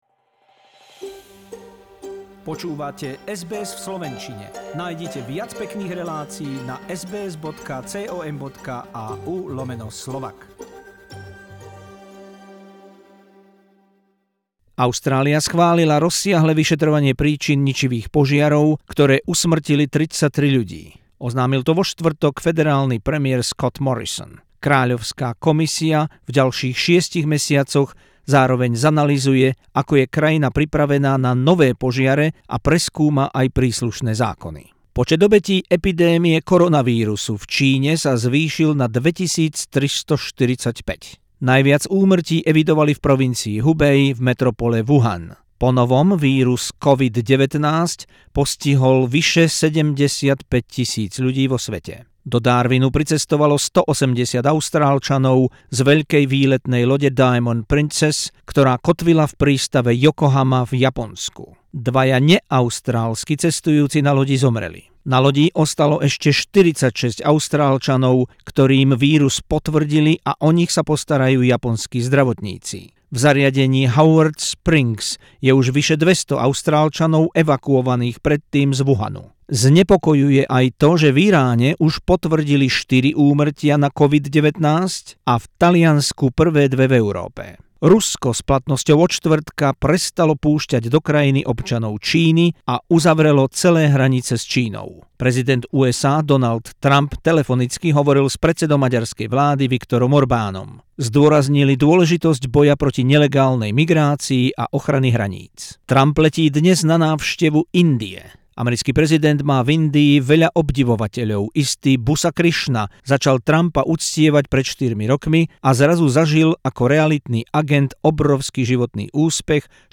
News bulletin in Slovak language on SBS Radio Australia from Sunday 8th March 2020.